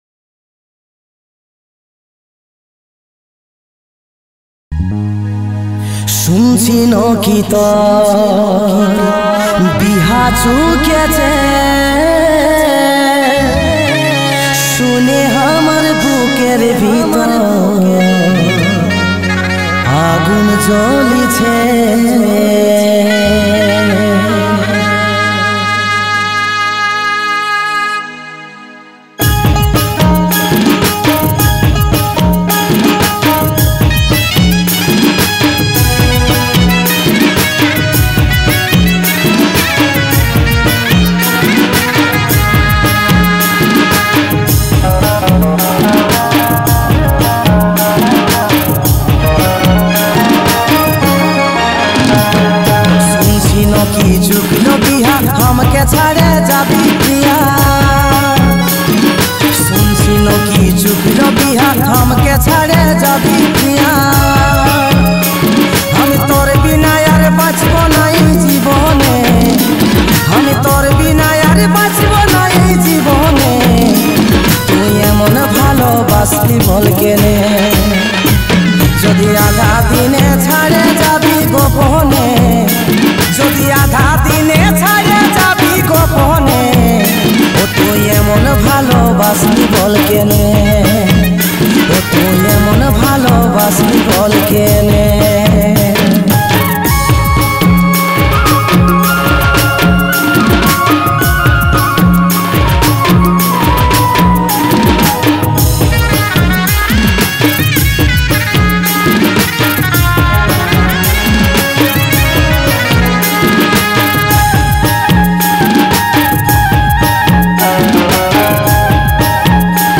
Purulia Gana